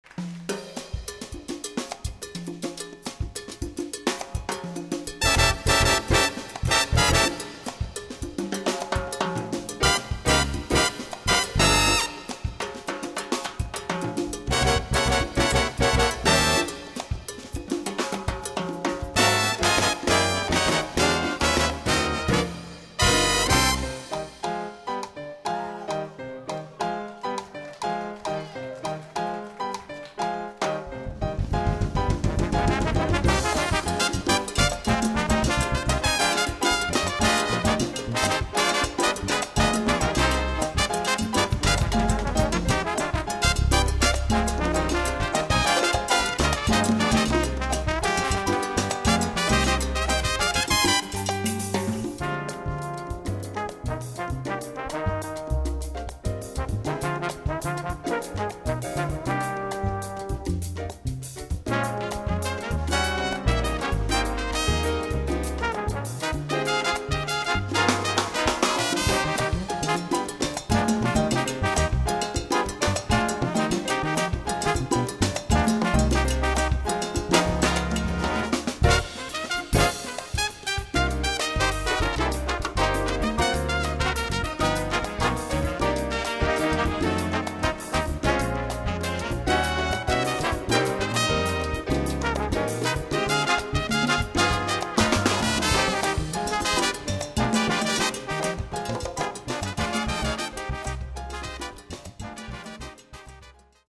Category: combo
Style: mambo
Solos: timbale, bass